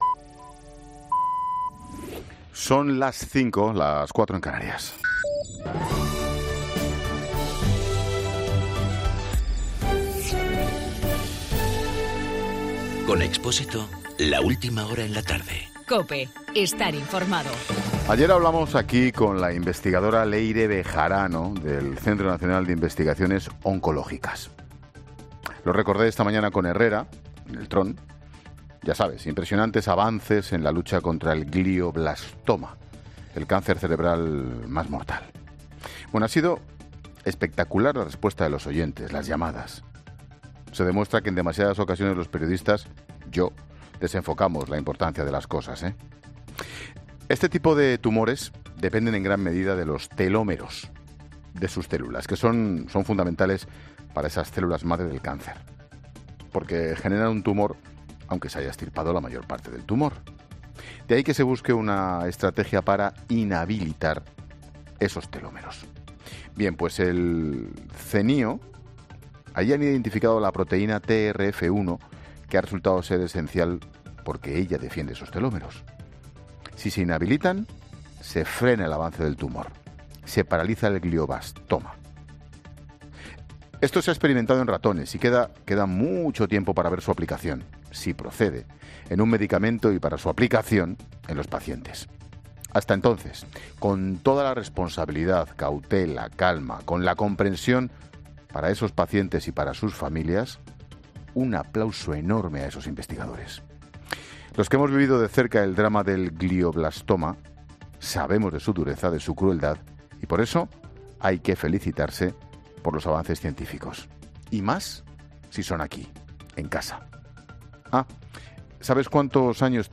AUDIO: Expósito analiza en su monólogo de las 17 horas el descubrimiento del CNIO que ha permitido frenar el glioblastoma, uno de los tumores...